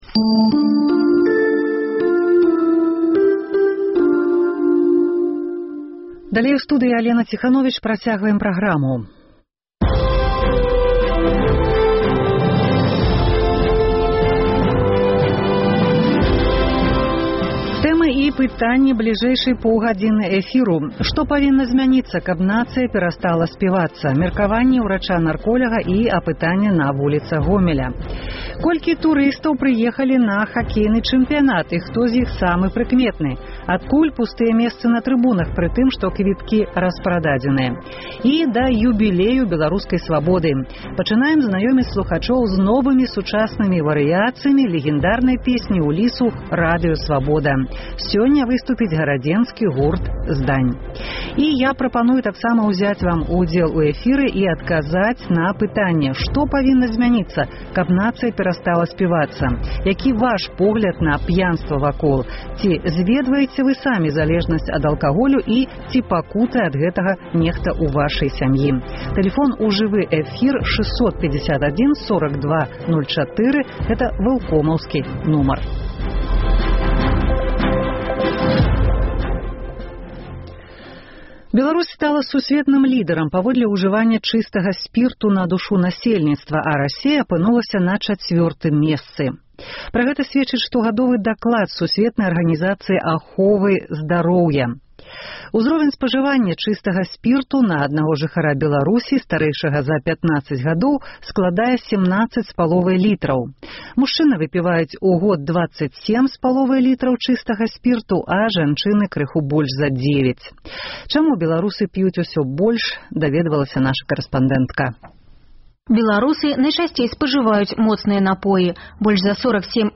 Што павінна зьмяніцца, каб нацыя перастала сьпівацца – меркаваньне ўрача-нарколяга і апытаньне на вуліцах Гомеля. Колькі турыстаў прыехалі на хакейны чэмпіянат і хто зь іх самы прыкметны.